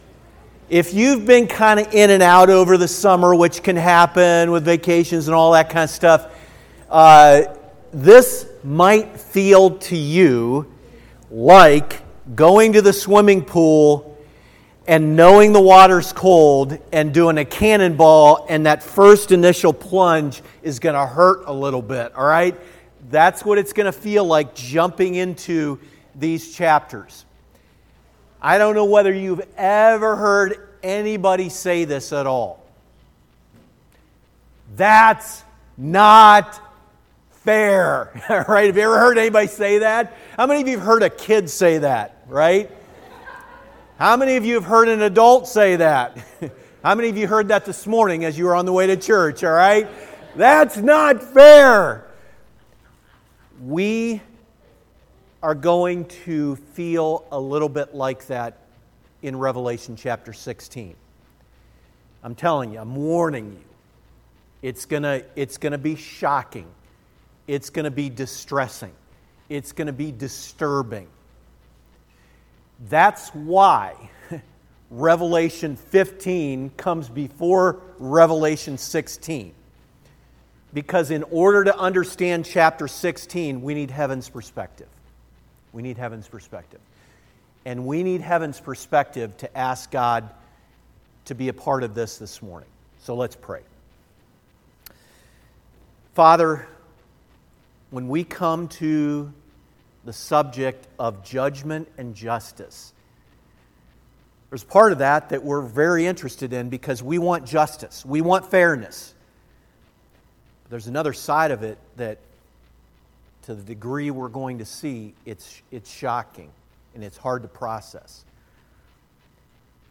Grace Be With You | SermonAudio Broadcaster is Live View the Live Stream Share this sermon Disabled by adblocker Copy URL Copied!